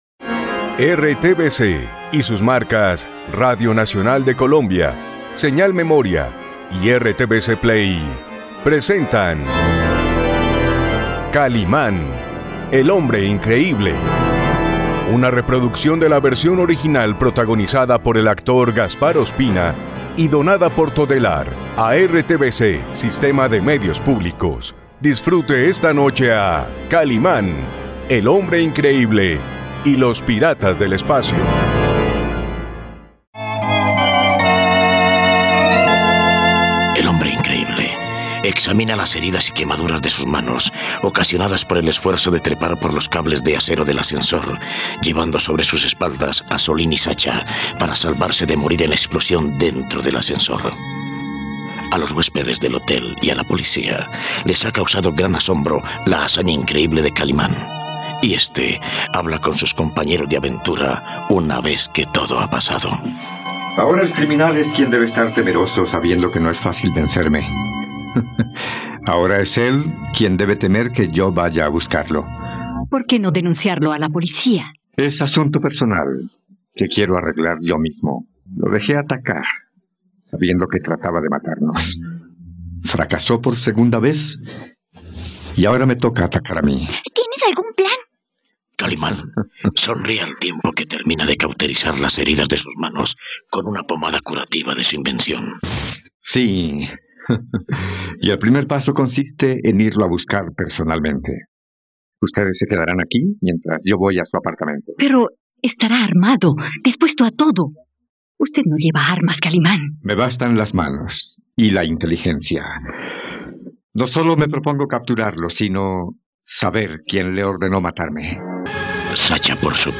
..En una habitación del hotel, Jaffa informa a Jazil sobre su fracaso en la misión de asesinar a Kalimán, ¿qué sucederá ahora? No te pierdas la radionovela de Kalimán y los piratas del espacio aquí, en RTVCPlay.